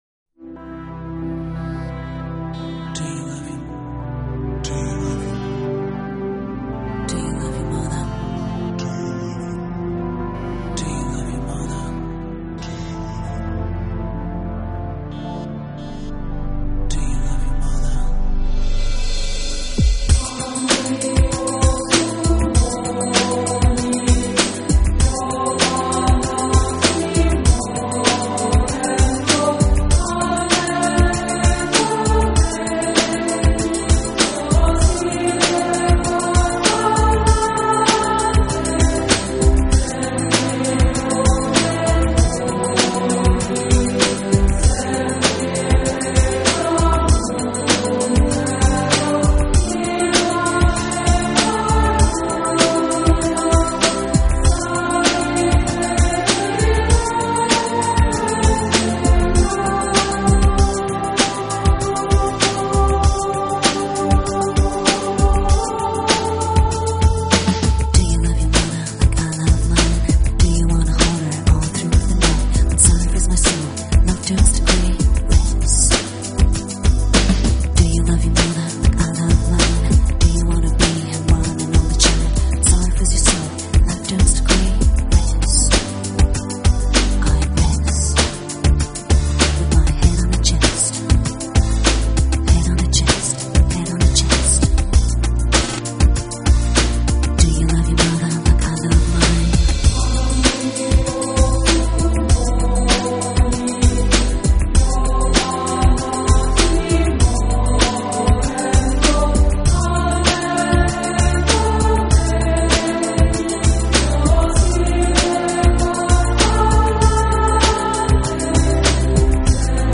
都是圣歌与现代器乐的融合，甚至乍听起来，还颇为相似。
是领唱上，都加重了女声的成分，因而整体风格都显得更柔和、更温暖。